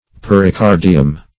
Pericardium \Per`i*car"di*um\, n. [NL., fr. Gr. perika`rdion,